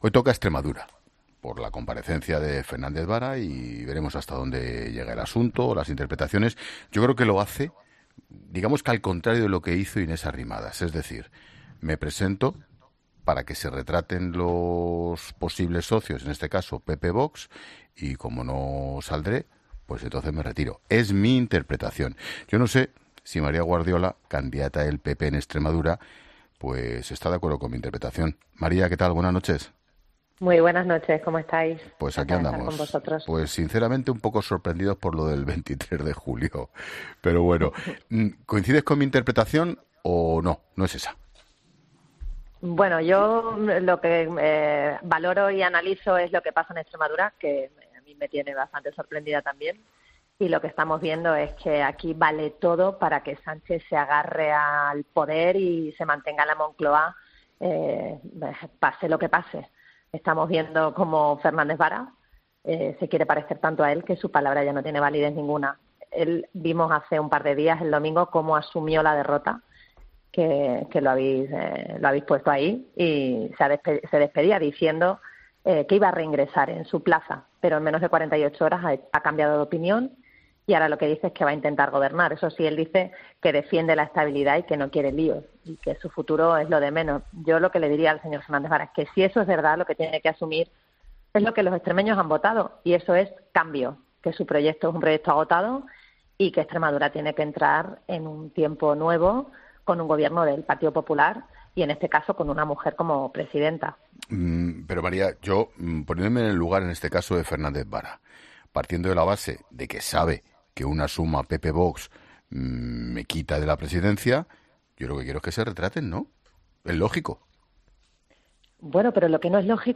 La candidata del PP el pasado domingo descarta en La Linterna emplear el criterio de la "lista más votada" en Extremadura: "Es un territorio especial"